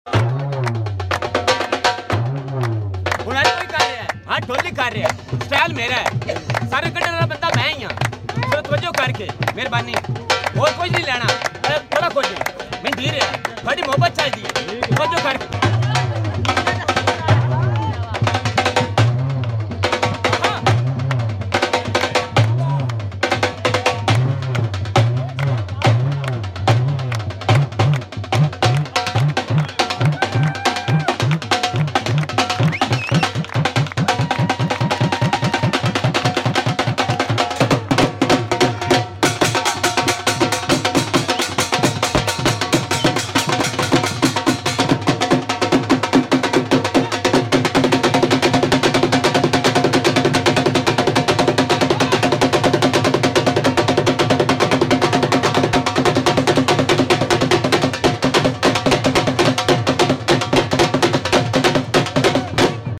beautiful Dhol beat